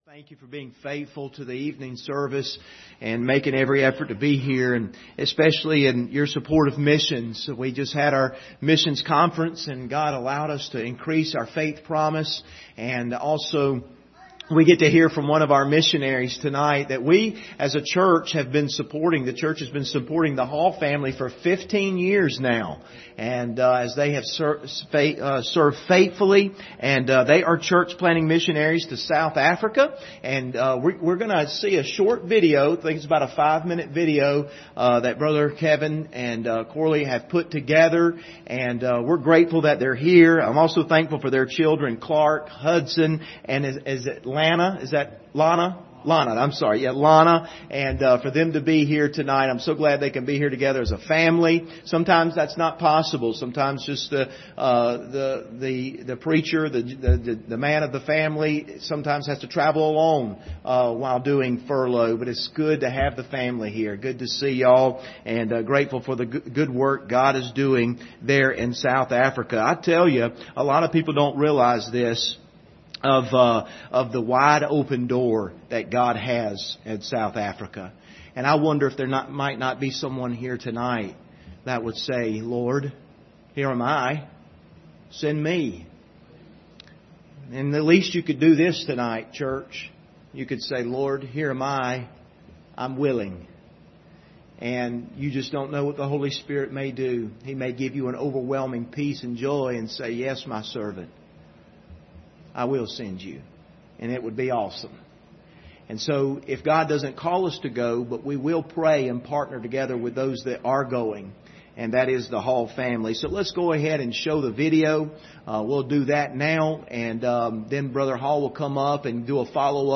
Service Type: Sunday Evening